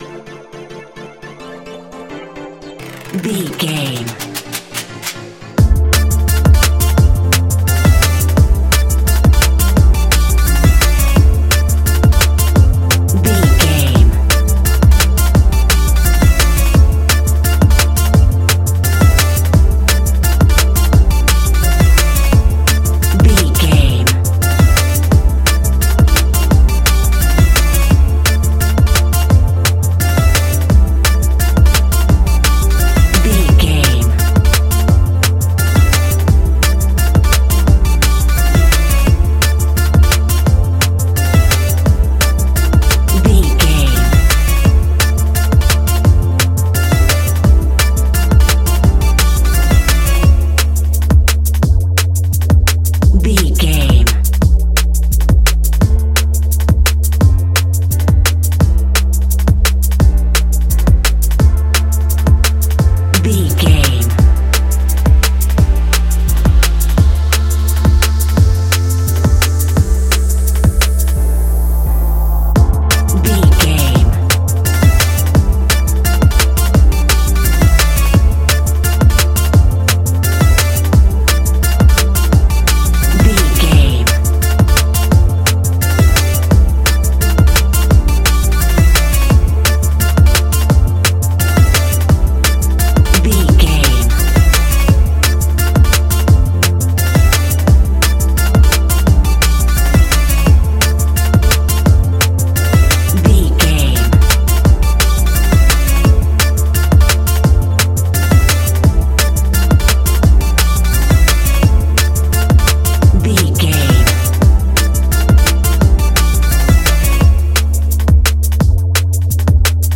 Ionian/Major
electronic
dance
techno
trance
synths
synthwave
instrumentals